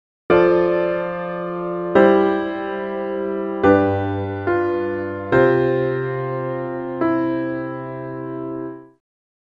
There are a number of sorts of cadences, some have an air of finality while others lead us further and further into the music (the one above is of the final sort).
cadence.mp3